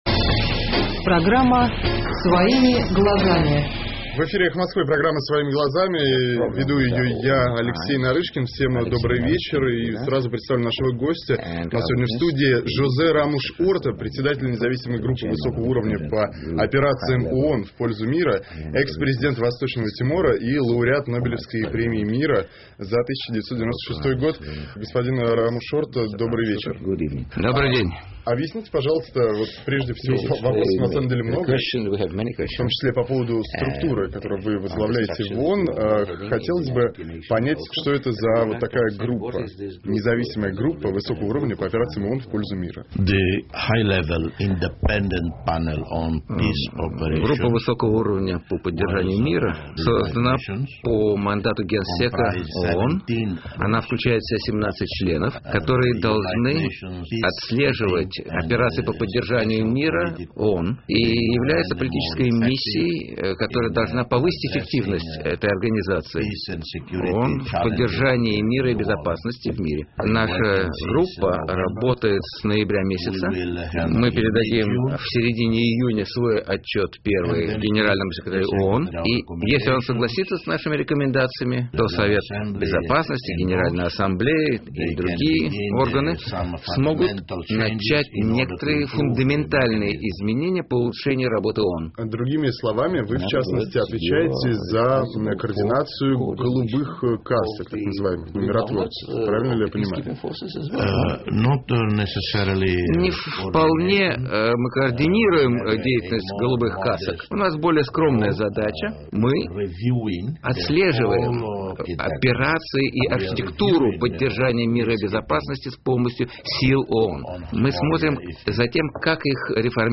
И сразу представлю нашего гостя. У нас в студии ЖозеРамуш-Орта, председатель независимой группы высокого уровня по операциям ООН в пользу мира, экс-президент Восточного Тимора, лауреат Нобелевской Премии Мира за 1996 год.